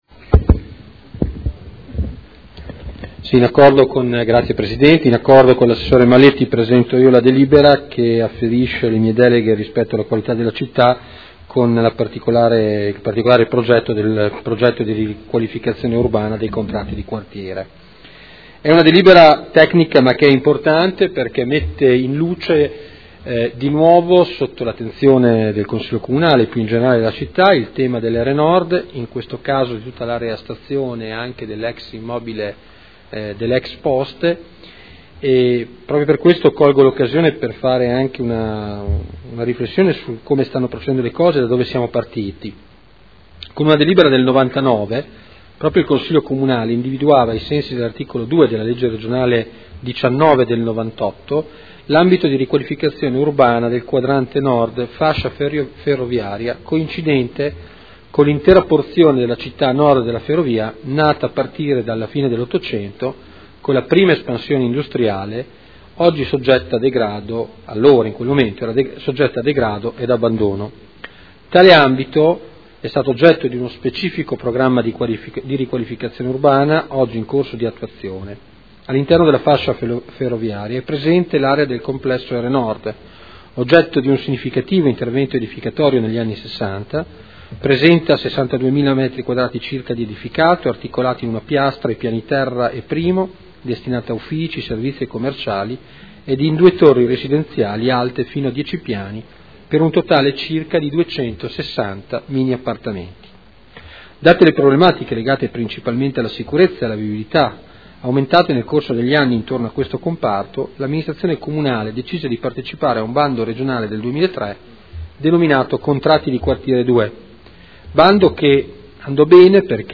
Seduta del 25/06/2012.